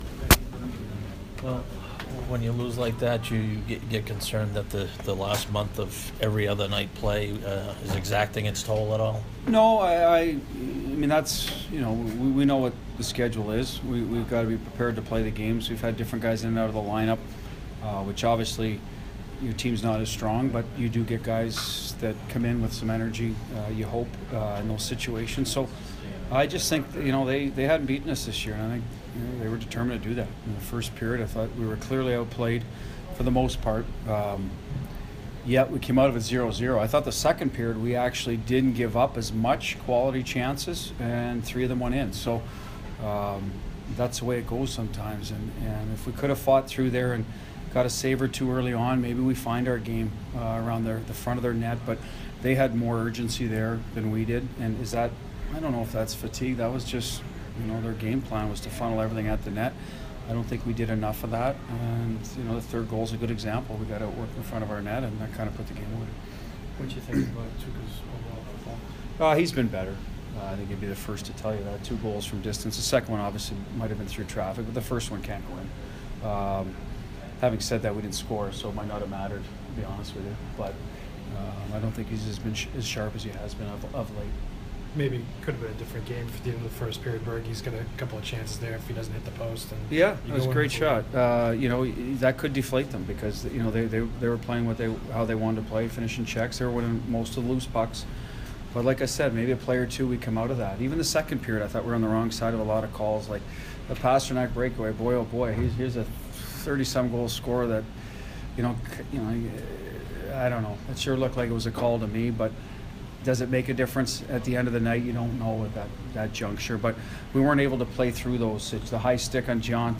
Bruins head coach Bruce Cassidy post-game 4/3